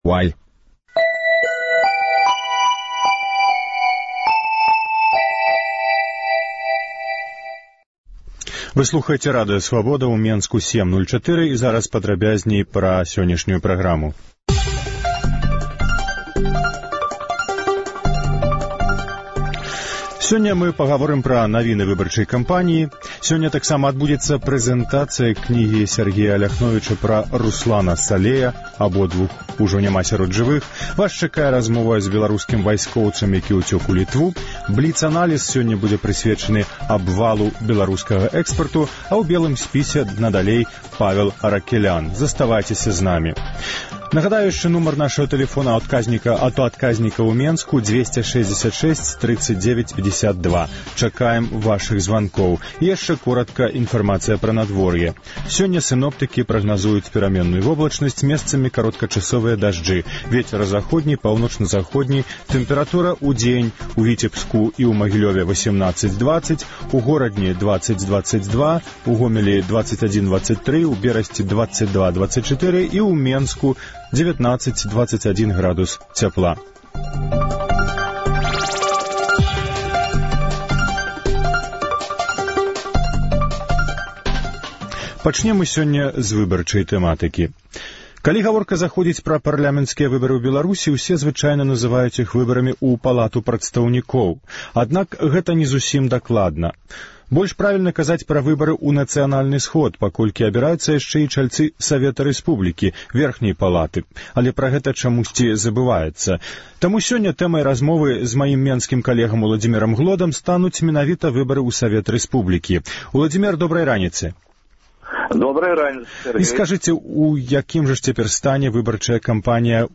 Інфармацыйны блёк: навіны Беларусі і сьвету. Паведамленьні нашых карэспандэнтаў, званкі слухачоў, апытаньні ў гарадах і мястэчках Беларусі.